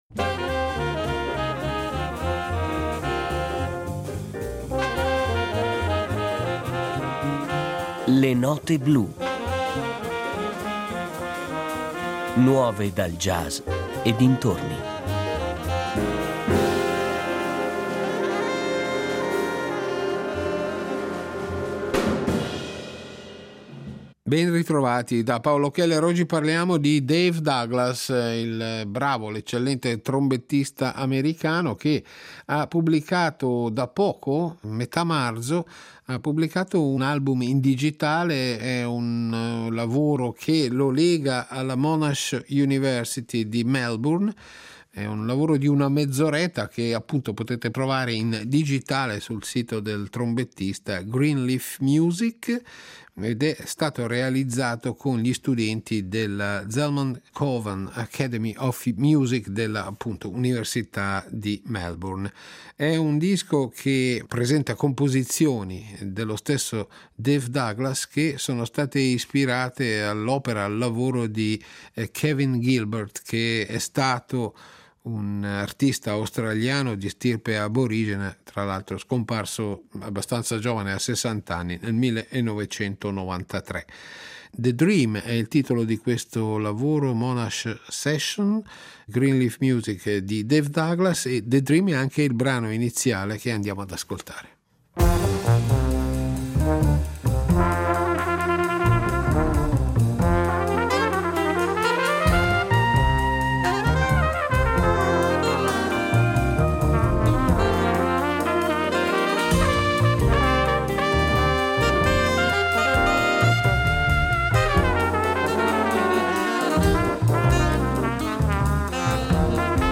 arrangiate per un ensemble di medie dimensioni